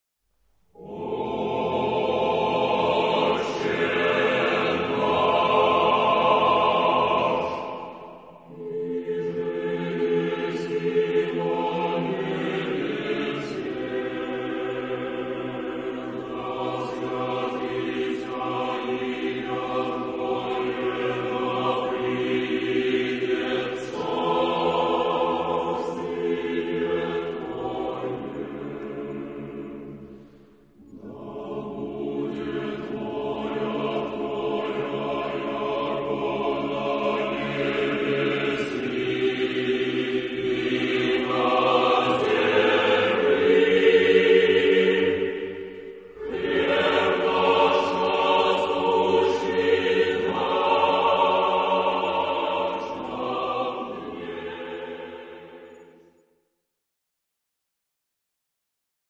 Type de choeur : SSAATTBB mixtes